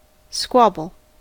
squabble: Wikimedia Commons US English Pronunciations
En-us-squabble.WAV